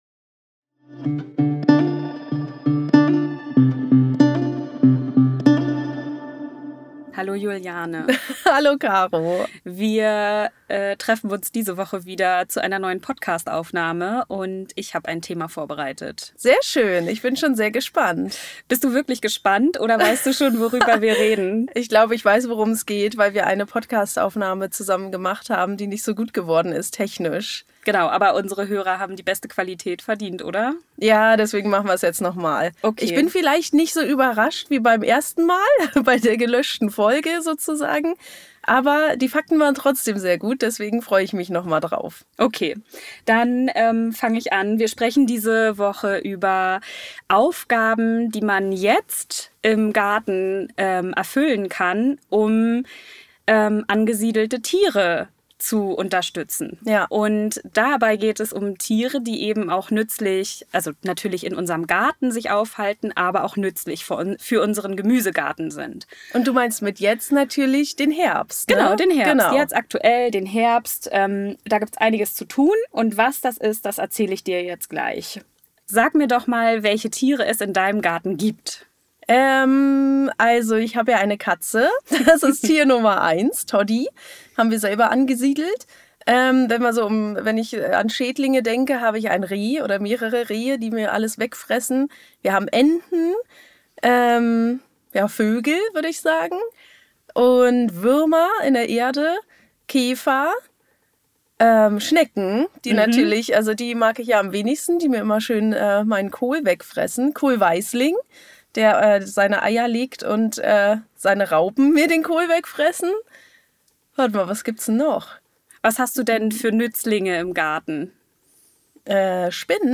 Die Folge, die wir zweimal aufnehmen mussten... weil wir die Qualität verbessern wollen! Heute geht's um die wichtigsten Garten-ToDos im Herbst und spannende Infos über unsere liebsten tierischen Nützlinge.